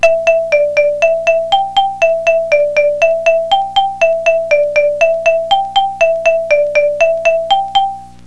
We can use loudness to accentuate one of the patterns, if you select an instrument on your synthesiser which is sensitive for loudness.